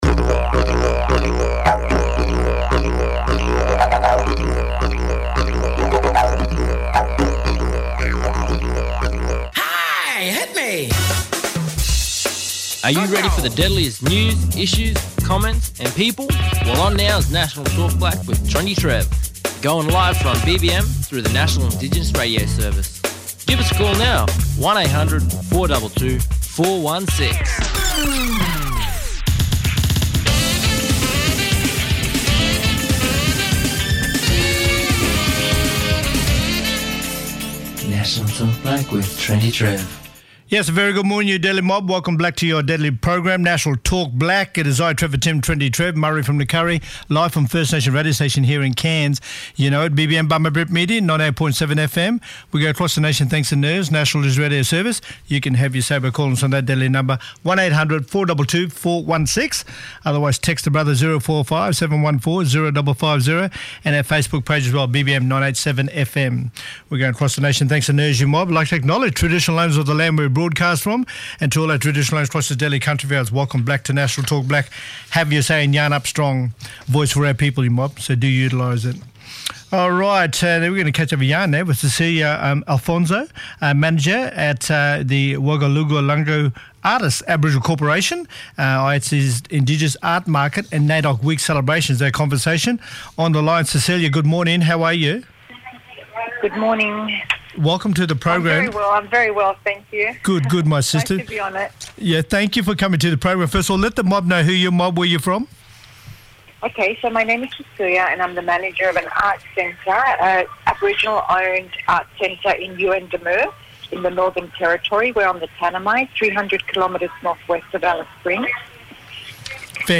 Linda Burney , Shadow Minister for Indigenous Australians talking about Naidoc 2020 and Labor’s commitment to Uluru.